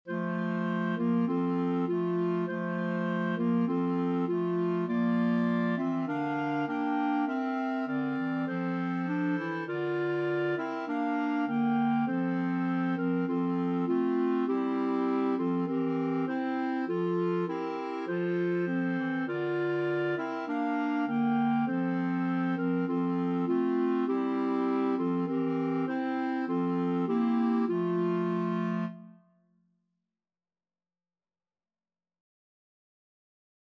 4/4 (View more 4/4 Music)
Classical (View more Classical Clarinet Quartet Music)